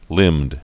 (lĭmd)